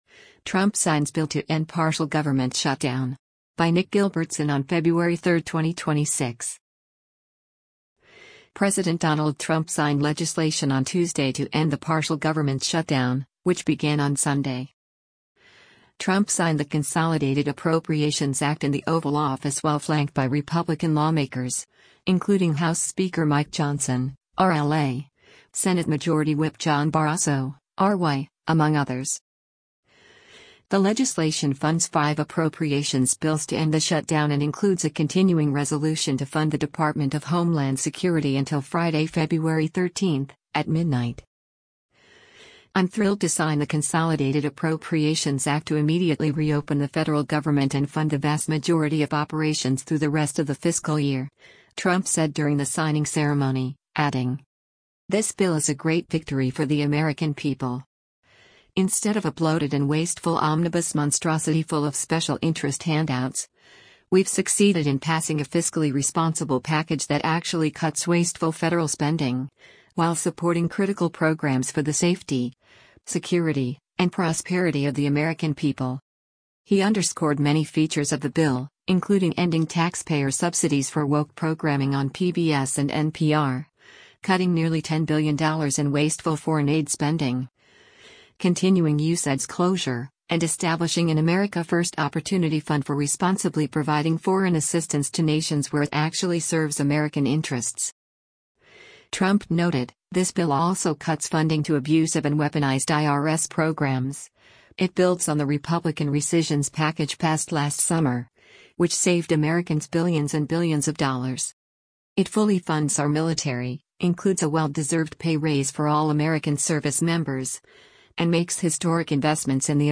US President Donald Trump signs a funding bill to end a partial government shutdown in the